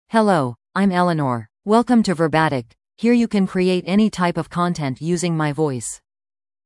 Eleanor — Female English (United States) AI Voice | TTS, Voice Cloning & Video | Verbatik AI
Eleanor is a female AI voice for English (United States).
Voice sample
Female
Eleanor delivers clear pronunciation with authentic United States English intonation, making your content sound professionally produced.